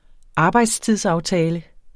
Udtale [ ˈɑːbɑjdstiðs- ]